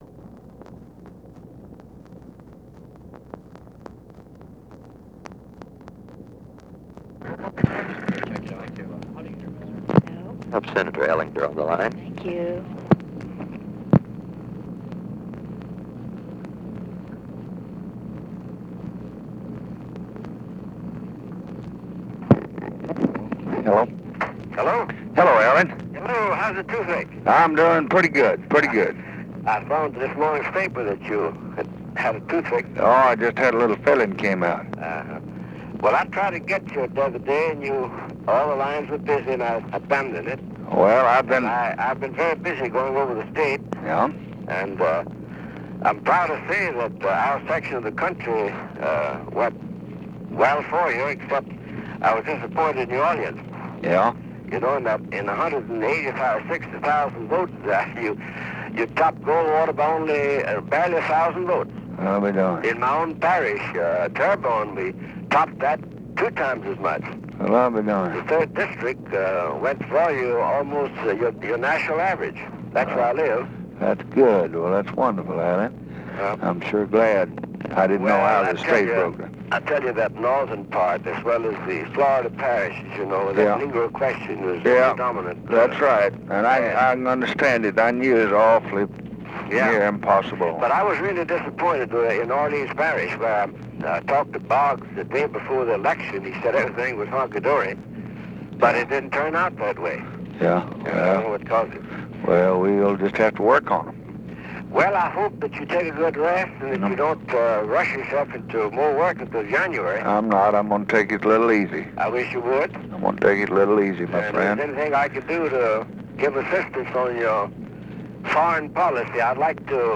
Conversation with ALLEN ELLENDER, November 8, 1964
Secret White House Tapes